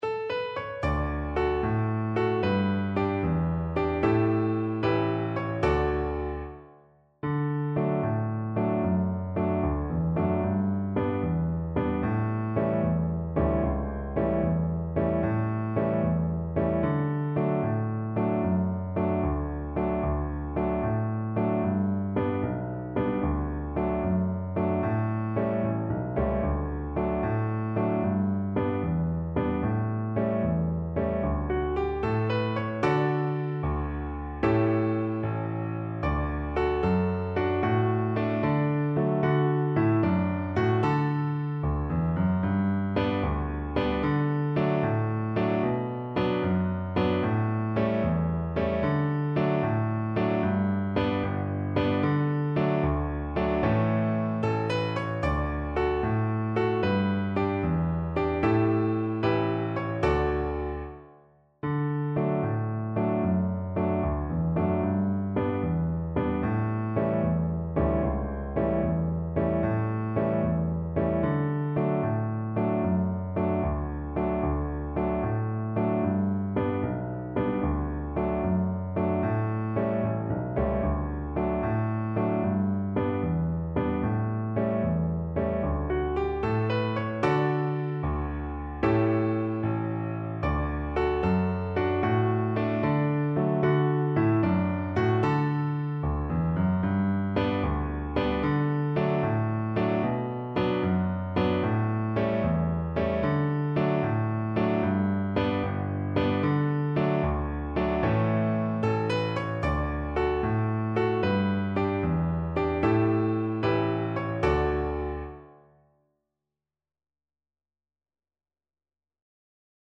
Viola
6/8 (View more 6/8 Music)
A4-B5
D major (Sounding Pitch) (View more D major Music for Viola )
Allegro .=c.100 (View more music marked Allegro)
Traditional (View more Traditional Viola Music)